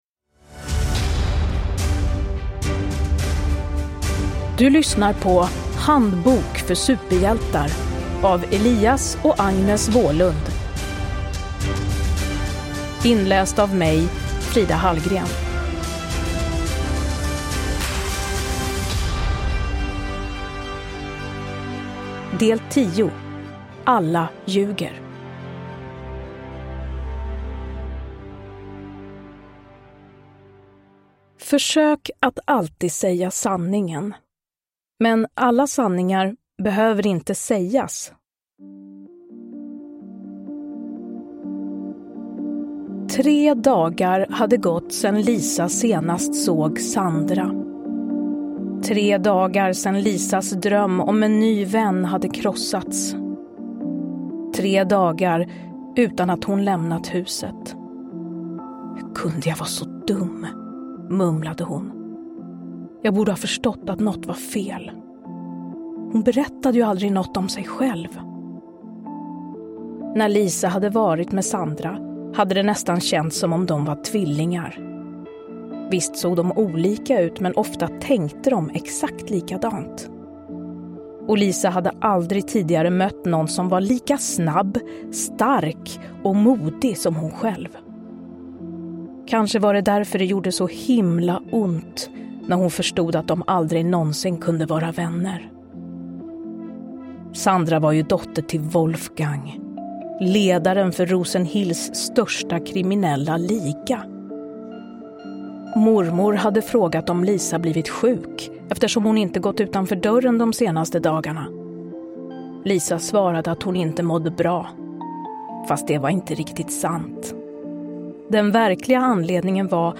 Handbok för superhjältar. Alla ljuger – Ljudbok
Uppläsare: Frida Hallgren